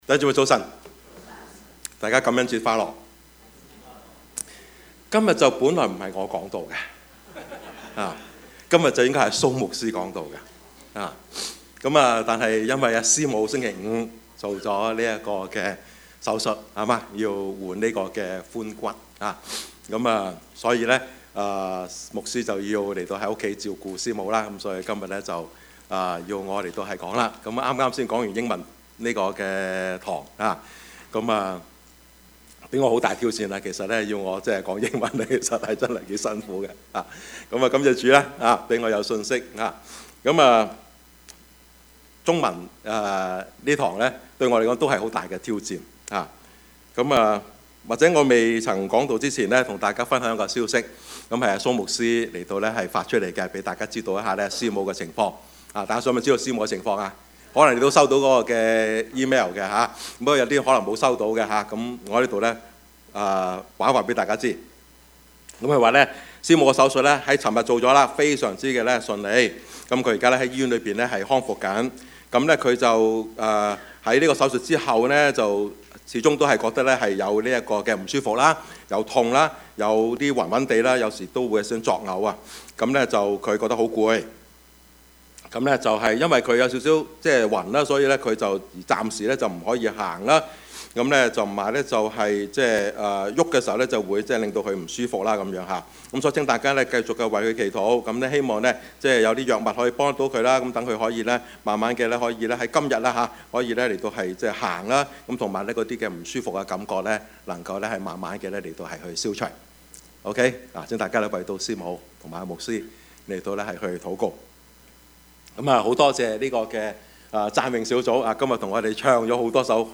Service Type: 主日崇拜
Topics: 主日證道 « 我是誰?